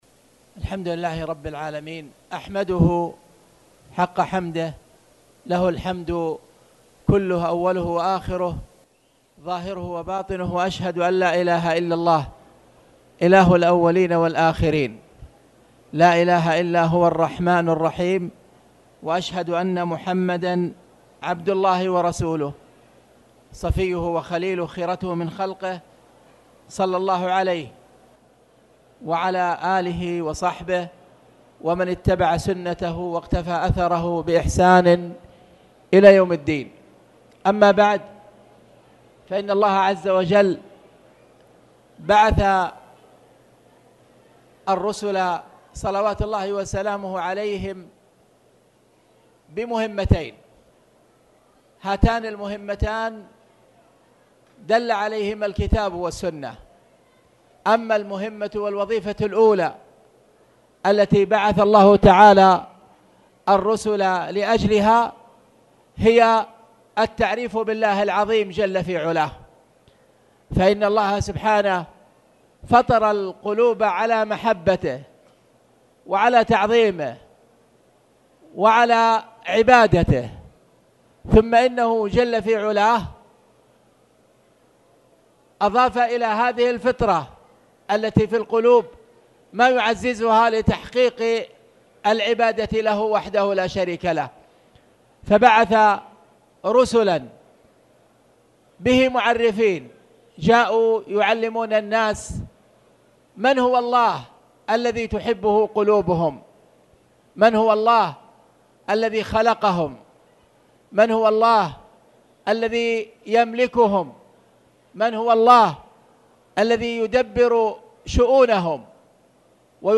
تاريخ النشر ٦ محرم ١٤٣٨ هـ المكان: المسجد الحرام الشيخ